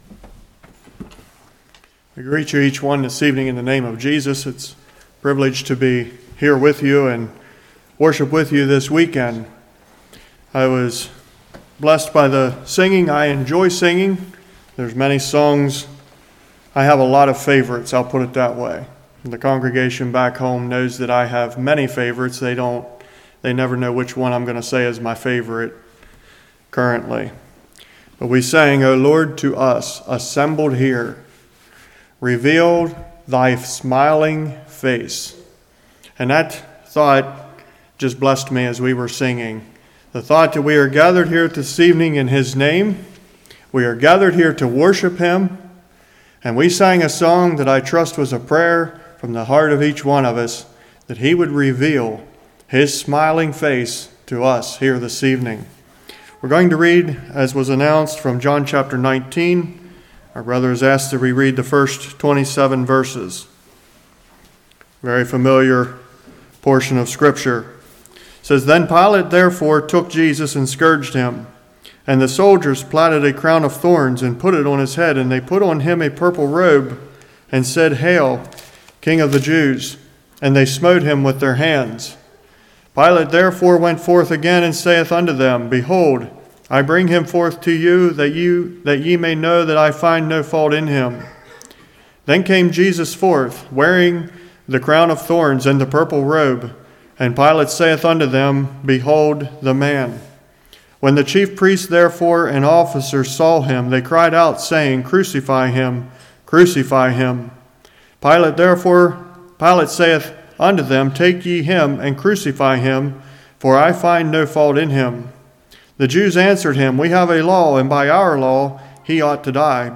Service Type: Love Feast